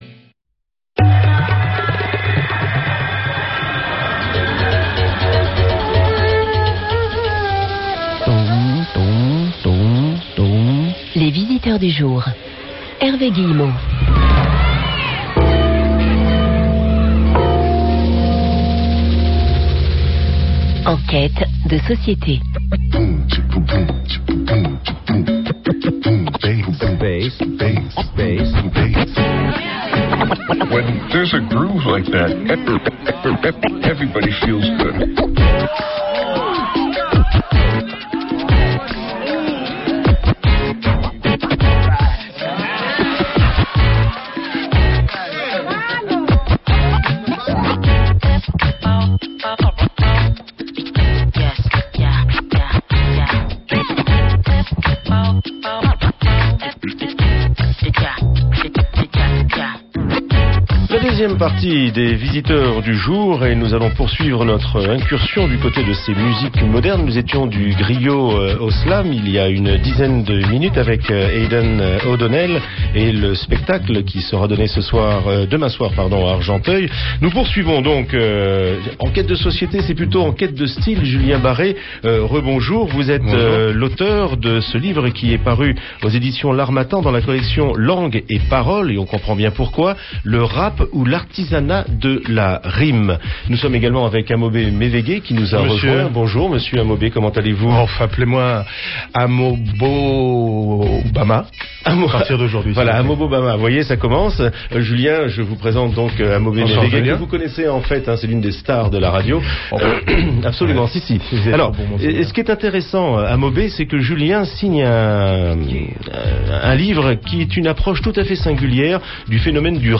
Débat avec les poètes
au Festival Étonnants Voyageurs en mai 2011